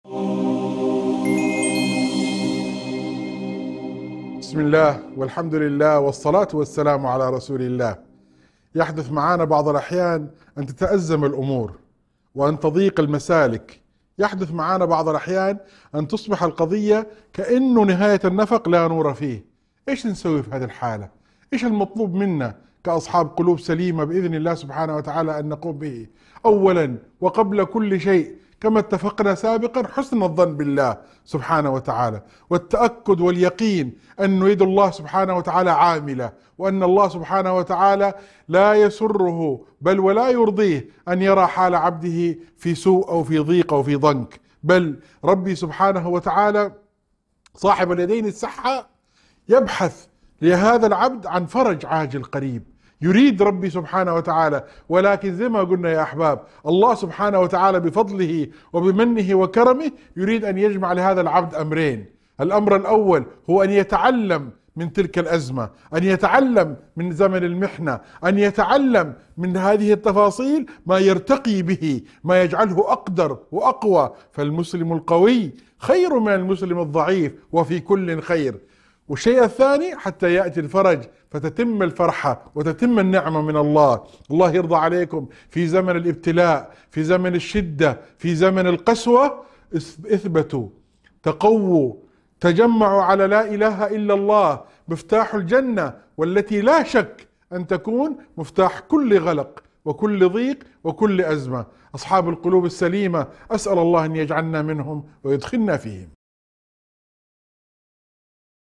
موعظة مؤثرة تذكر بفضل الله تعالى ورحمته بعباده في أوقات الشدة والضيق. تؤكد على أهمية الصبر وحسن الظن بالله واليقين بأن الفرج قادم، وأن المحن تكون سبيلاً للتعلم والارتقاء. تختتم بالتذكير بفضائل لا إله إلا الله وأهمية الثبات على الإيمان.